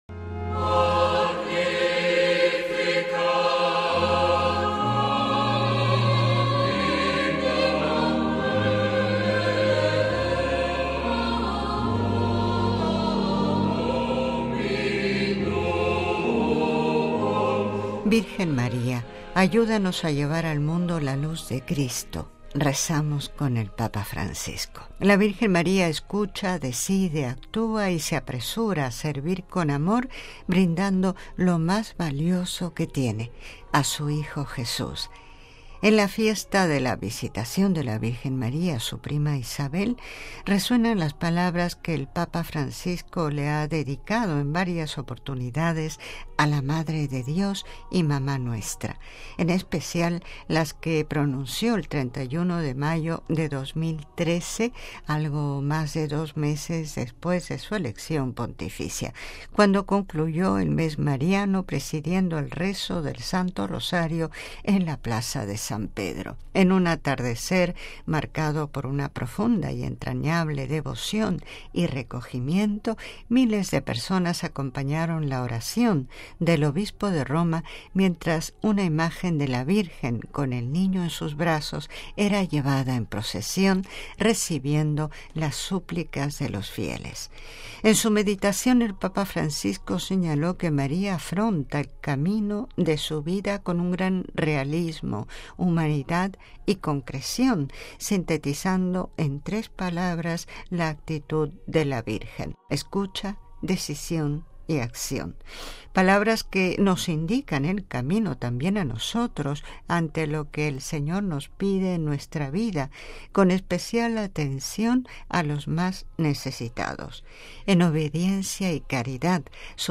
Cuando concluyó el mes mariano, presidiendo el rezo del Santo Rosario en la Plaza de San Pedro.
En un atardecer marcado por una profunda y entrañable devoción y recogimiento, miles de personas acompañaron la oración del Obispo de Roma, mientras una imagen de la Virgen con el Niño en sus brazos era llevada en procesión, recibiendo las súplicas de los fieles.